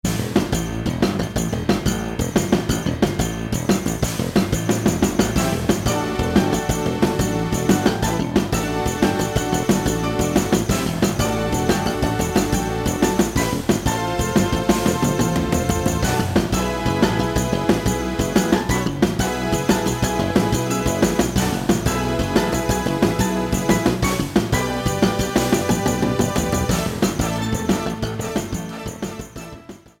Trimmed and fade out